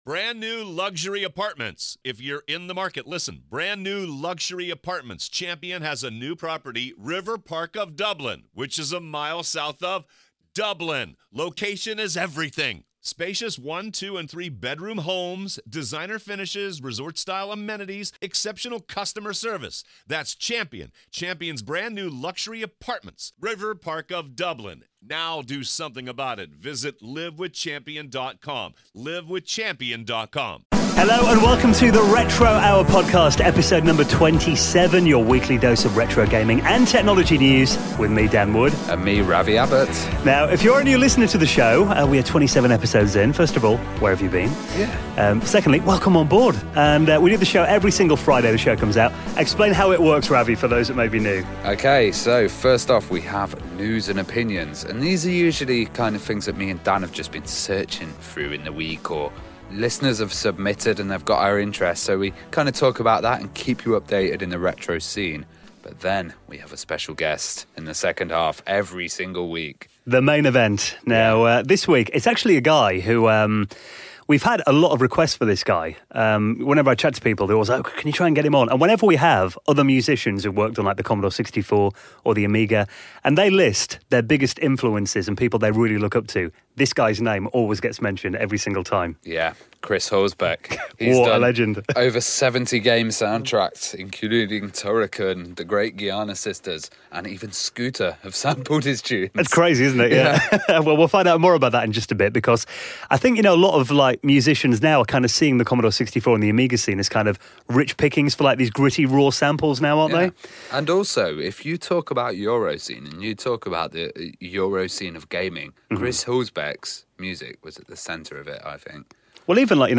The Retro Hour - Episode 27 (Chris Huelsbeck Interview)